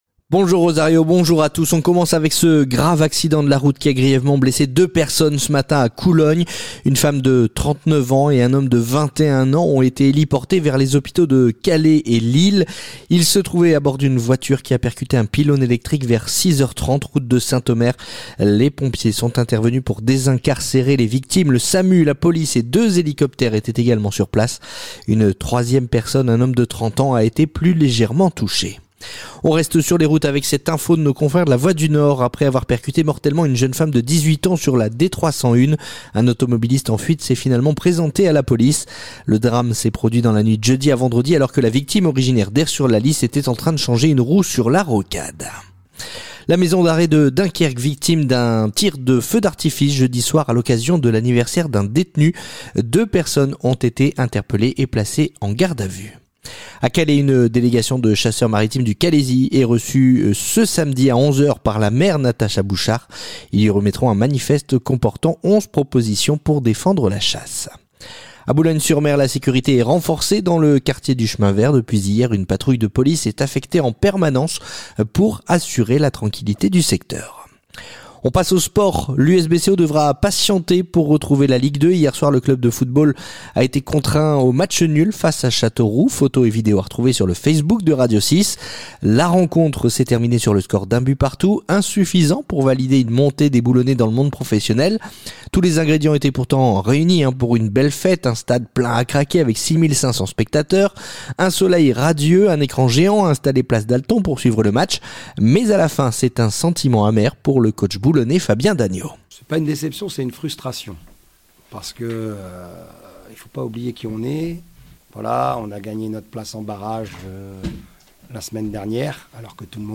Le journal du samedi 17 mai